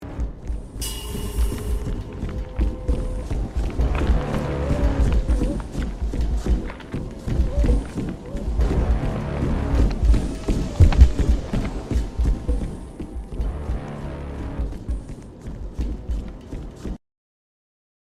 Roblox Color or Die Monster ambience
The sound the monster makes when near the player (Roblox Color or Die)
roblox-color-or-die-monster-ambience.mp3